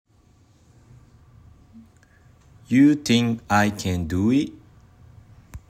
より自然なカタカナ ユー　ティンク　アイ　ケン　ドゥー　イッ？
「think」と「it」の語尾は消え入るように。